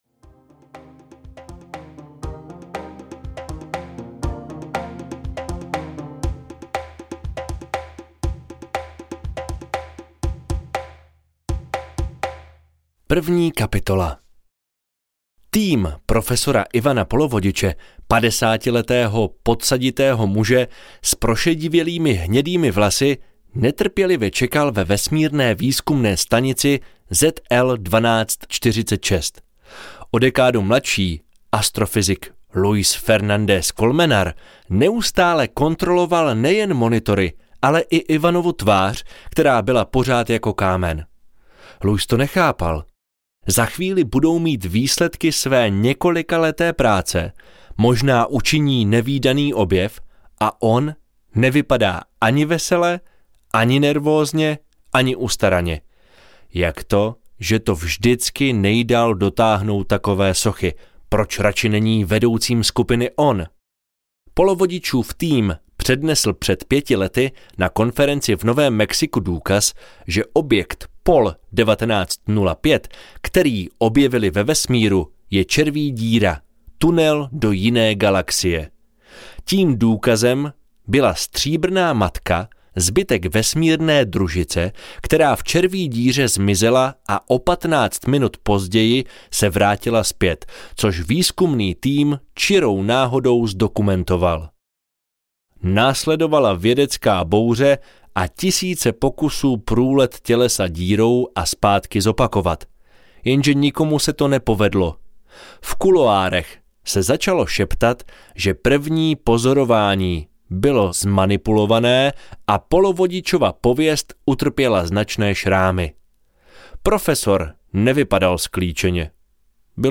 Mexabo audiokniha
Ukázka z knihy
mexabo-audiokniha